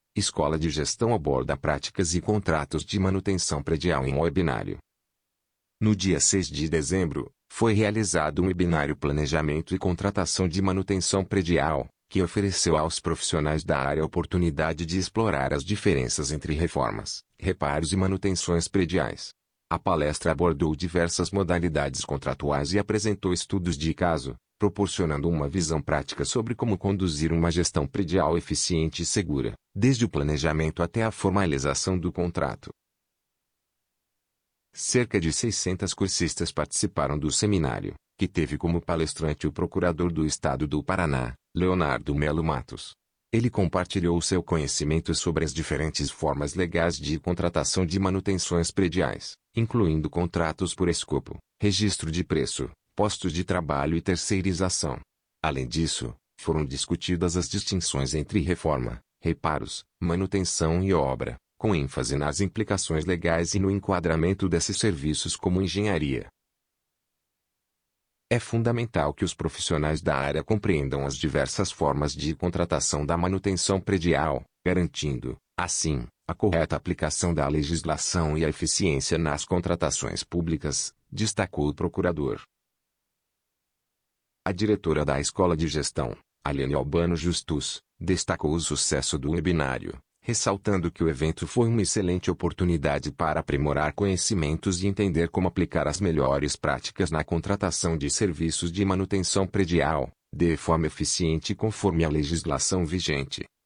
audionoticia_praticas_e_contratos_de_manutencao_predial.mp3